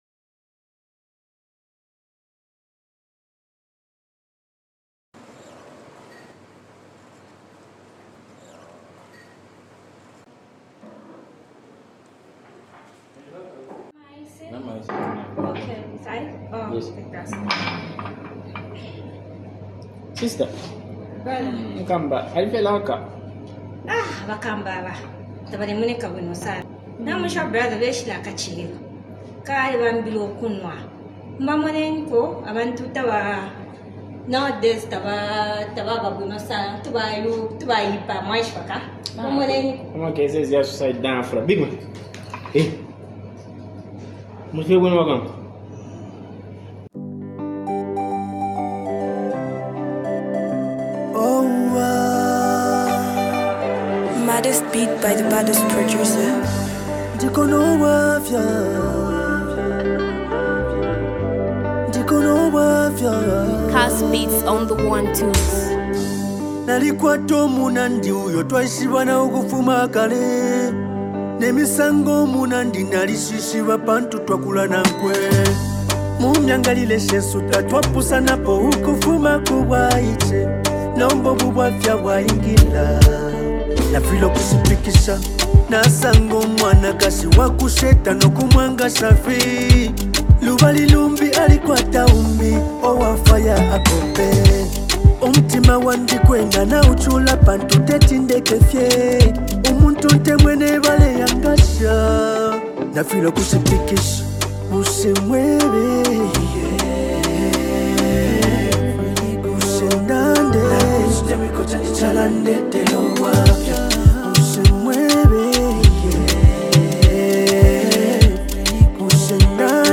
is a deeply reflective and emotional song
Kalindula sound blended with modern elements